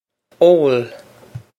Pronunciation for how to say
ole
This is an approximate phonetic pronunciation of the phrase.